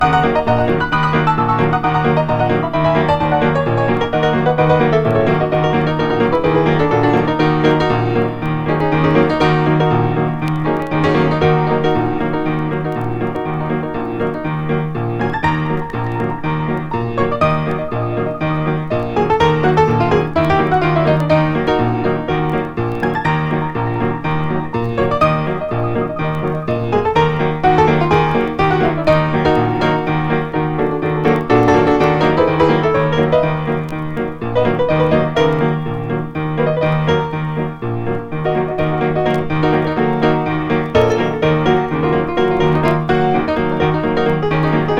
本盤は、ピアノソロ作品集。
ピアノソロとはいえ、奇妙奇天烈っぷりはしっかり刻まれてます。